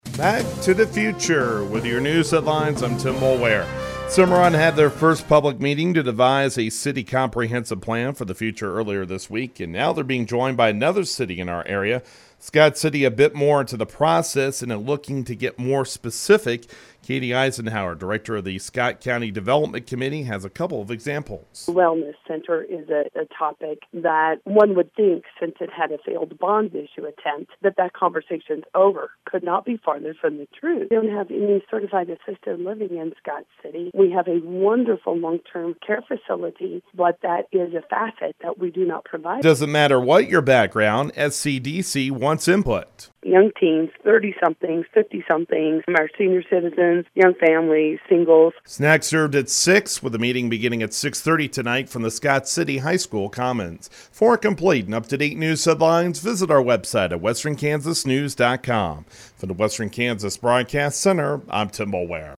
*On-air story*